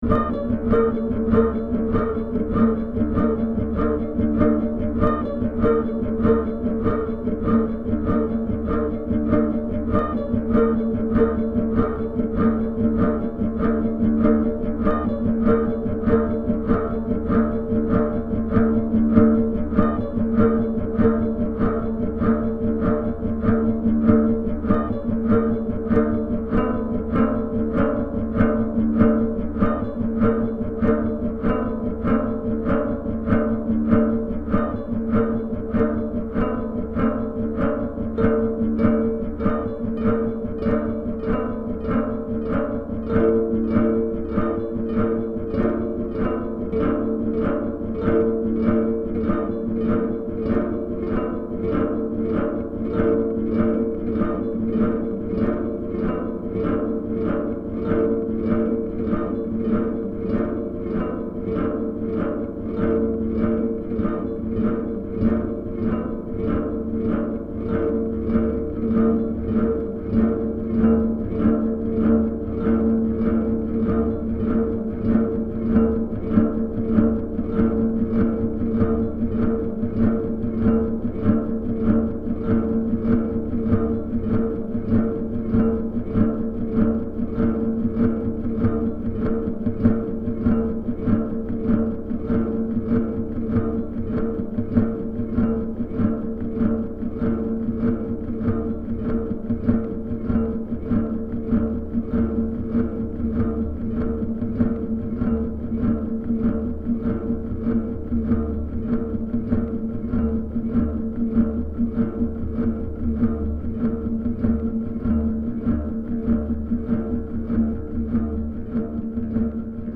This recording was actually before the previous post when I was in Zaragoza.